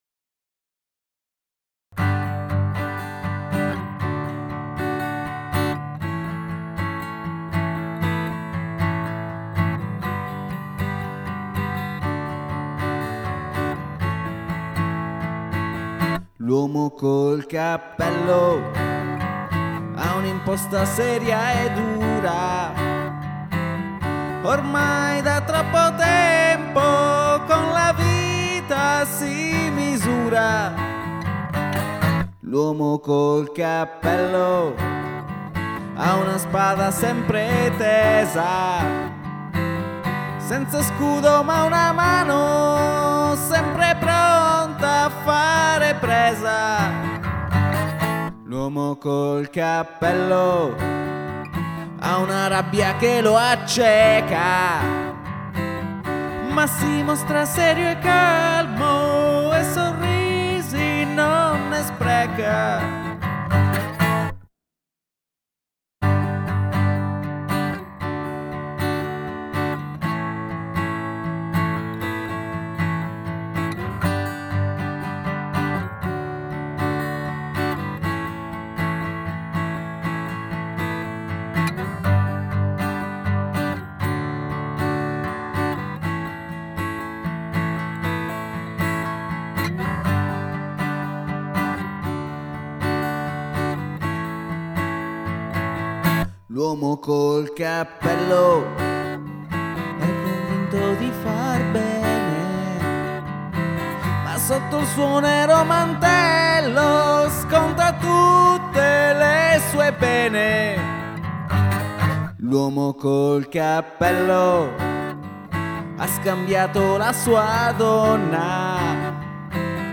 Luogo esecuzioneParma
GenerePop